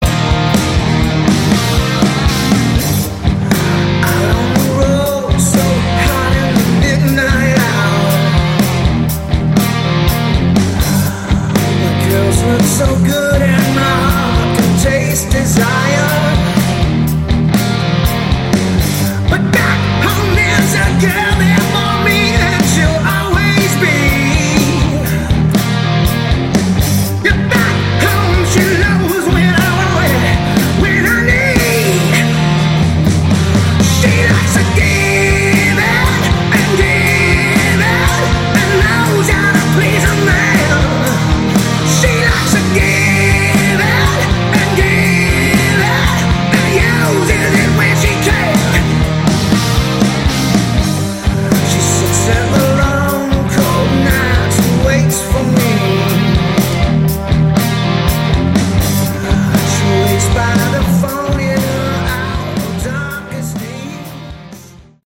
Category: Hard Rock
lead vocals, keyboards, acoustic guitar
drums, percussion
guitar, keyboards
bass, vocals